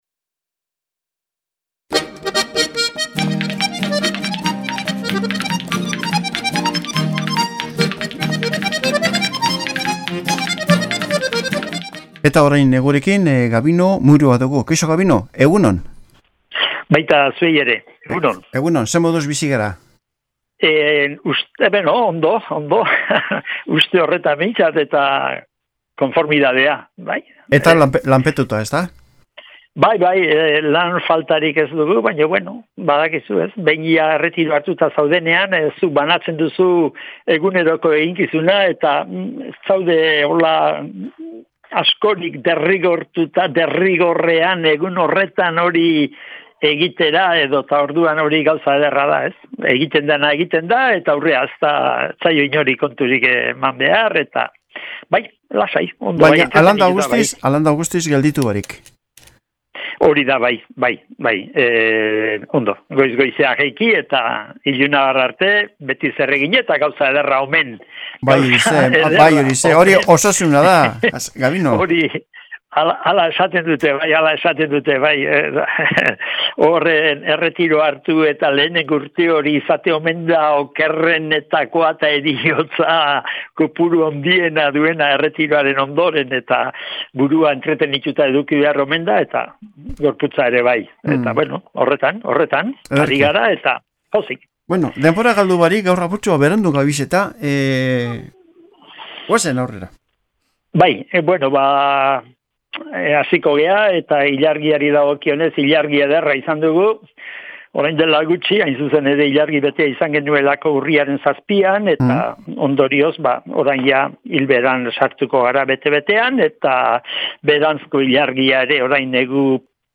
Alkarrizketa